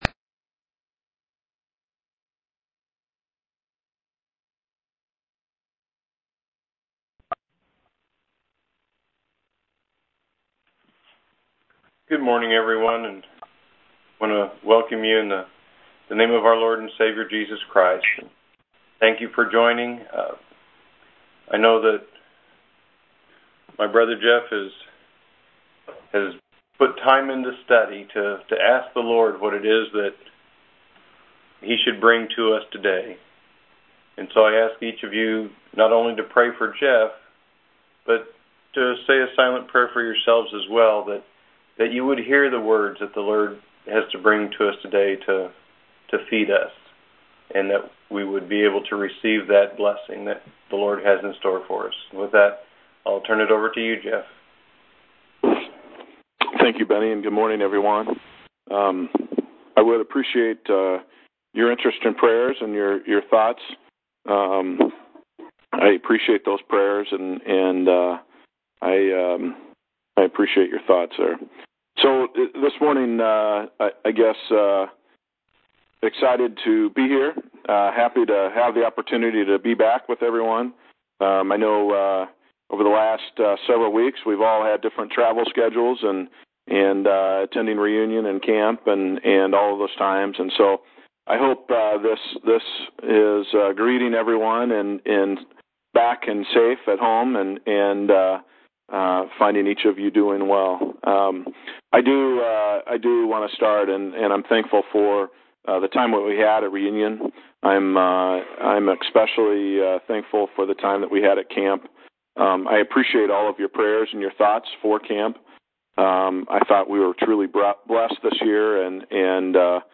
8/14/2011 Location: Teleconference Event